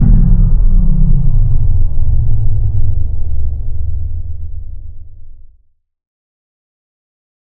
Low End 25.wav